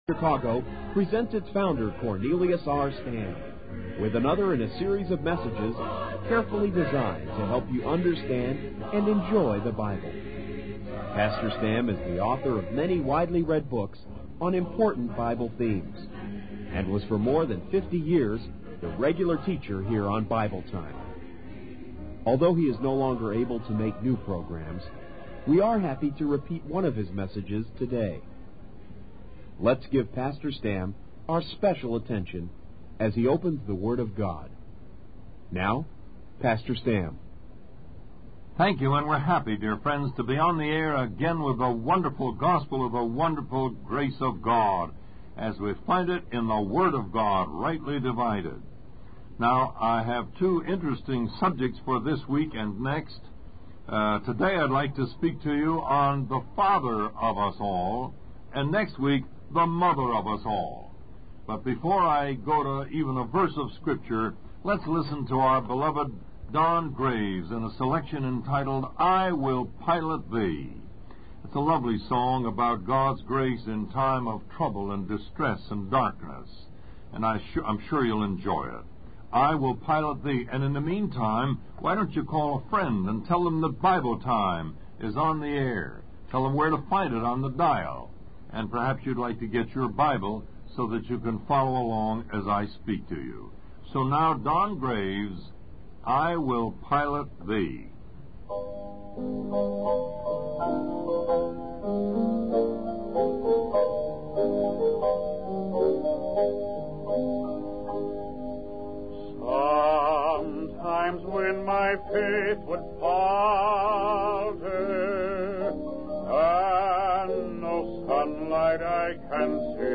When you're done, explore more sermons from this series.